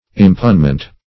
Search Result for " impugnment" : The Collaborative International Dictionary of English v.0.48: Impugnment \Im*pugn"ment\, n. The act of impugning, or the state of being impugned.